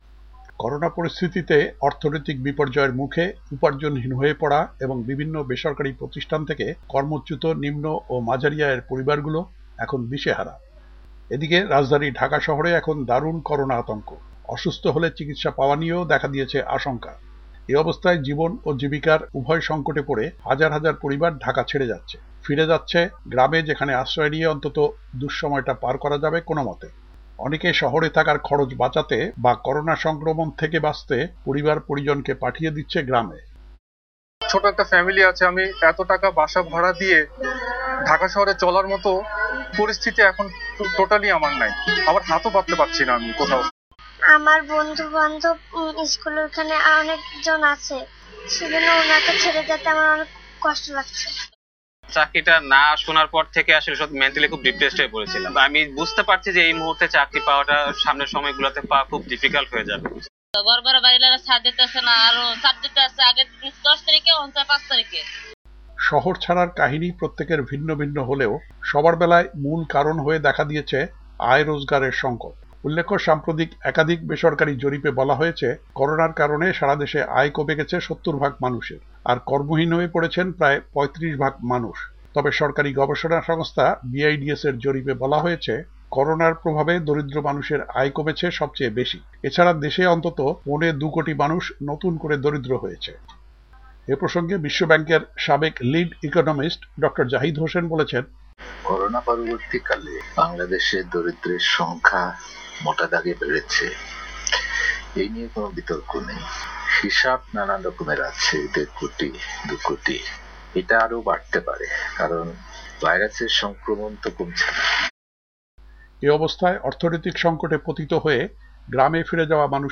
করোনায় অর্থনৈতিক সংকটে দিশেহারা মানুষ: ভুক্তভোগী ও বিশ্লেষক প্রতিক্রিয়া